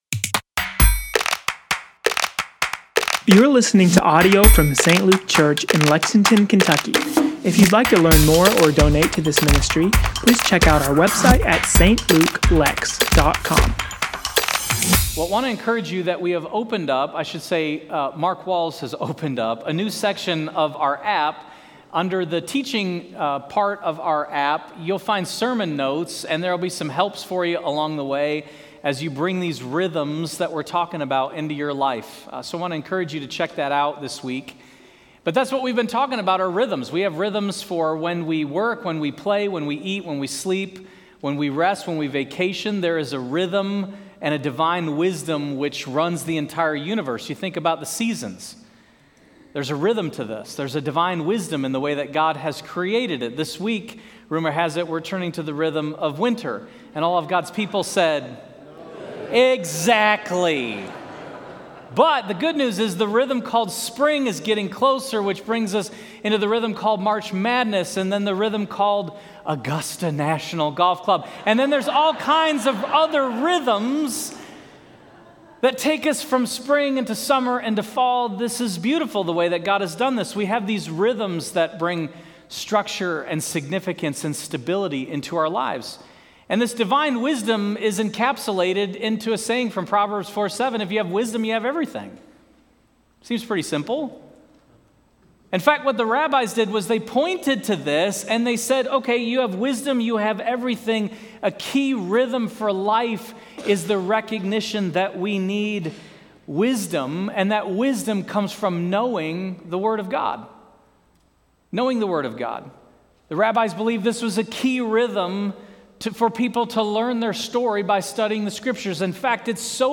St. Luke Church Lexington – Sermons & Teachings